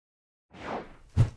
投球